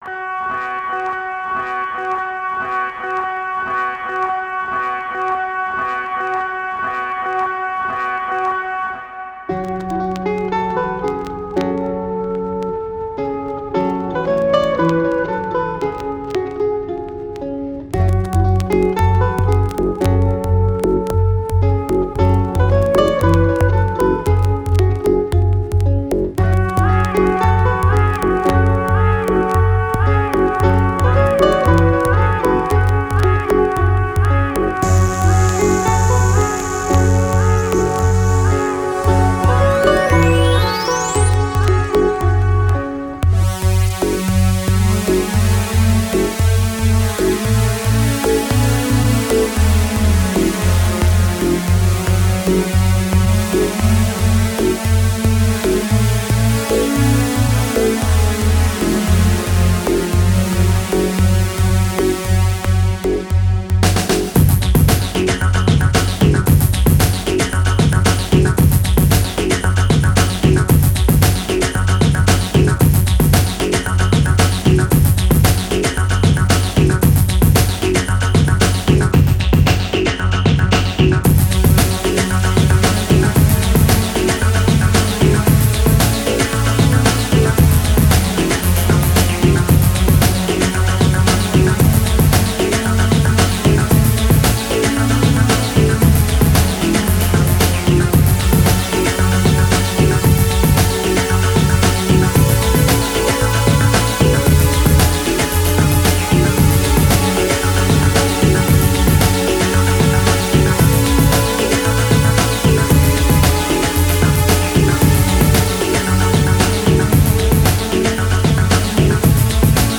RSAudio RSA 08/13/2018 We’ve got a great show for you all this week with a special look at EBM from Mexico and Poland courtesy of a couple of compilations that sadly aren’t on Amazon for me to link to.